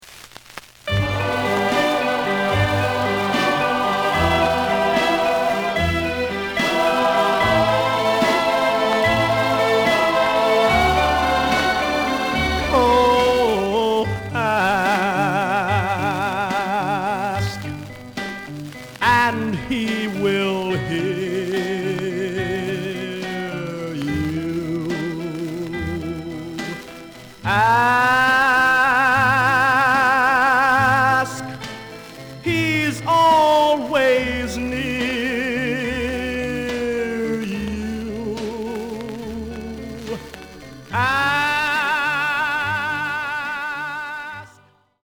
The listen sample is recorded from the actual item.
●Genre: Rhythm And Blues / Rock 'n' Roll
Some noise on both sides.)